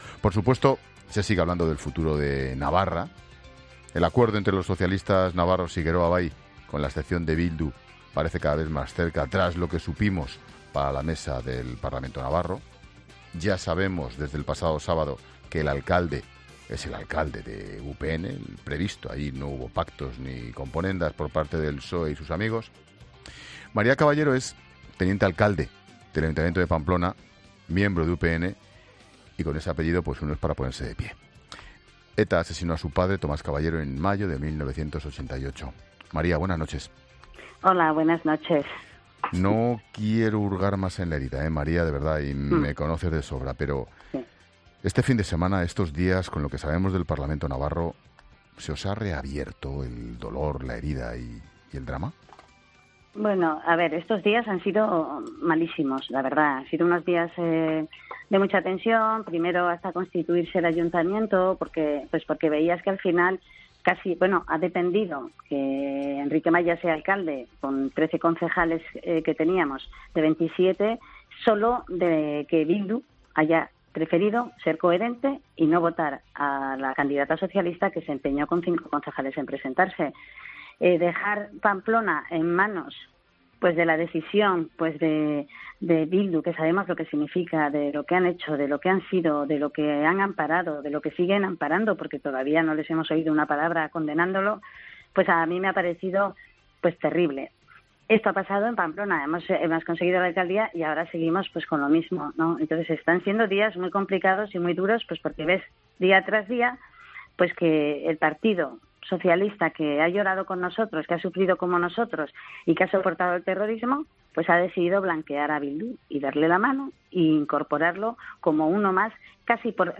Esta noche ha pasado por la Linterna María Caballero, teniente de alcalde del Ayuntamiento de Pamplona, miembro de UPN e hija de Tomás Caballero, asesinado por ETA en 1998.